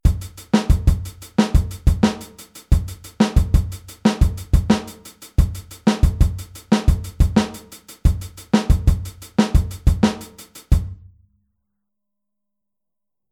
Aufteilung linke und rechte Hand auf HiHat und Snare